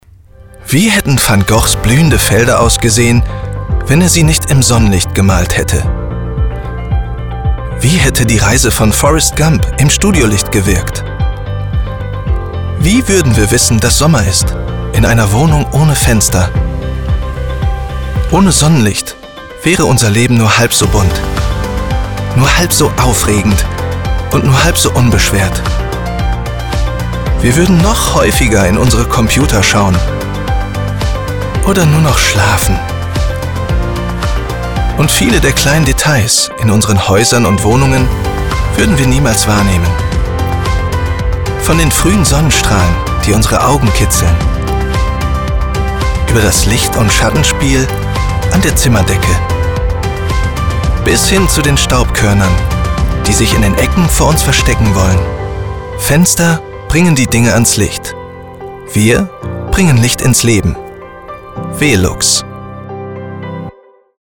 plakativ, markant, sehr variabel
Mittel minus (25-45)
Ruhrgebiet
Commercial (Werbung)